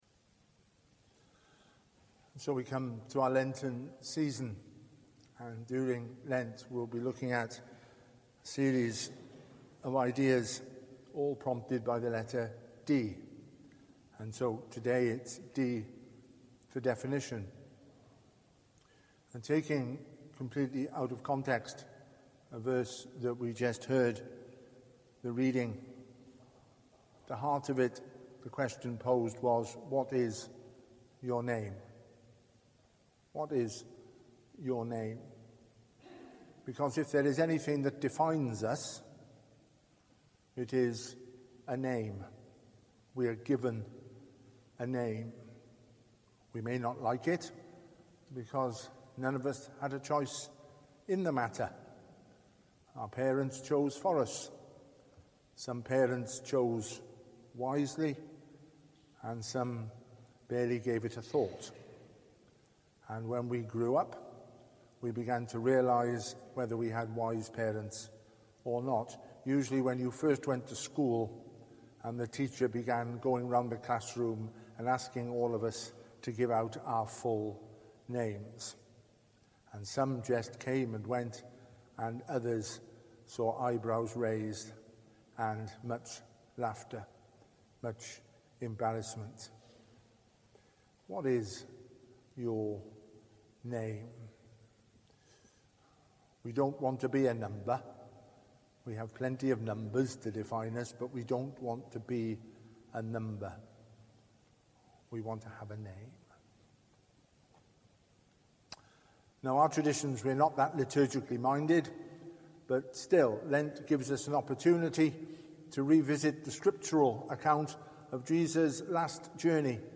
Lent 2020 Service Type: Family Service Minister